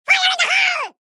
FIRE IN THE HOLE (loud ofc)
fire-in-the-hole-made-with-Voicemod-technology-1.mp3